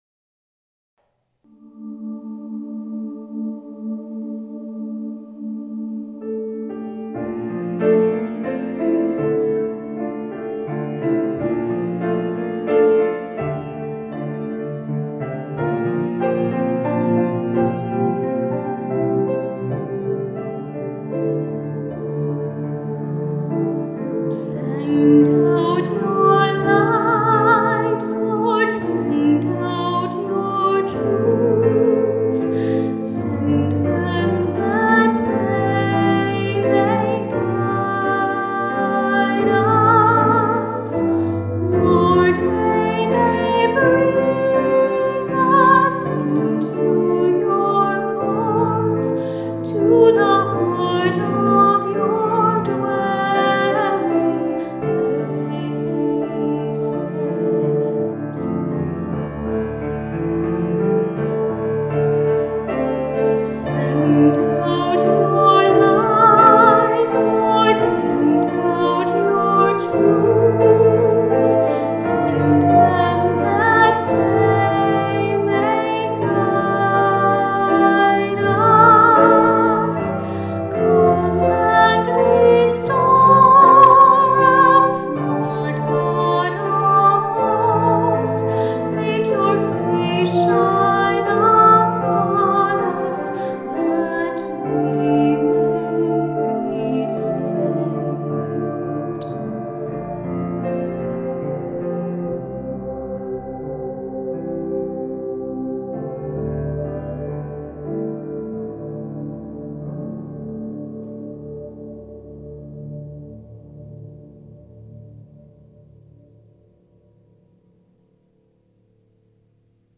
Send Out Your Light audio music track, sung prayer with lyrics based on three Psalm texts, especially appropriate for the Season of Light, Advent Christmas Epiphany